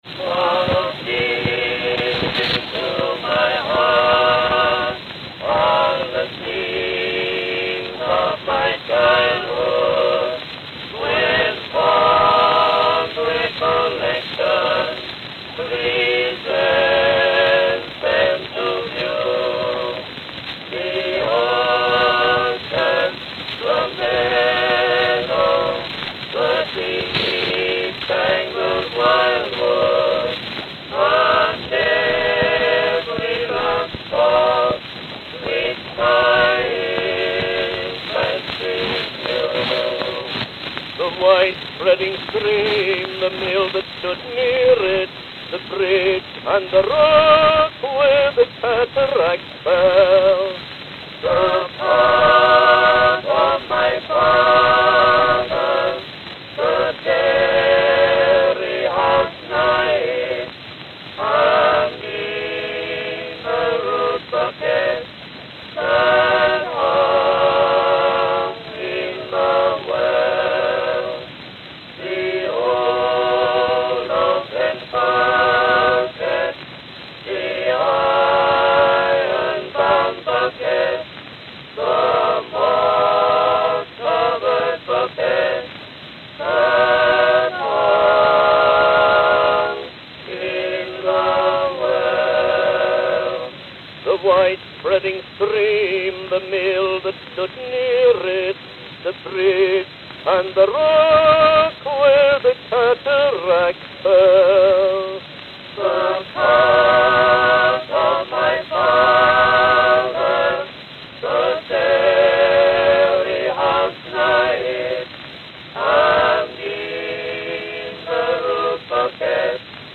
Columbia Quartette
Note: Rough and abrupt start (abrupt to exclude scratches).